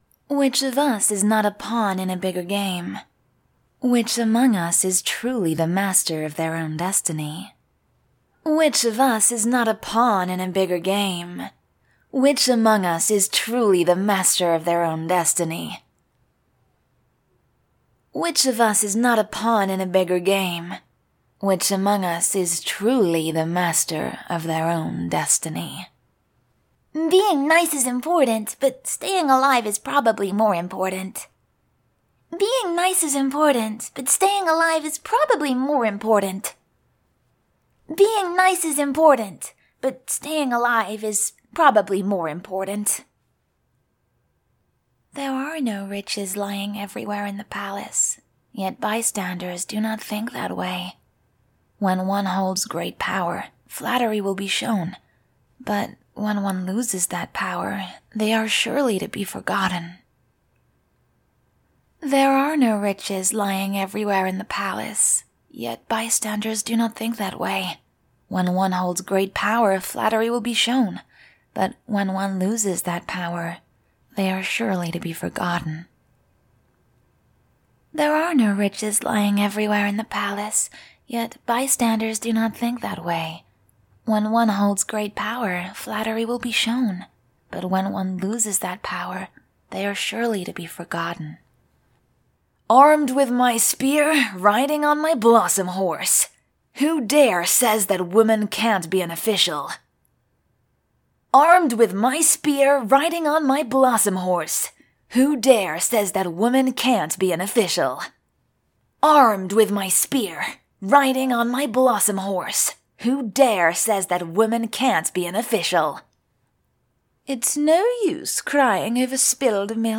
英式英语青年沉稳 、娓娓道来 、科技感 、积极向上 、时尚活力 、亲切甜美 、素人 、女专题片 、宣传片 、广告 、飞碟说/MG 、课件PPT 、工程介绍 、绘本故事 、动漫动画游戏影视 、旅游导览 、微电影旁白/内心独白 、80元/百单词女英102 美式英语英式英语 电影旁白 角色Black Survival_VG 沉稳|娓娓道来|科技感|积极向上|时尚活力|亲切甜美|素人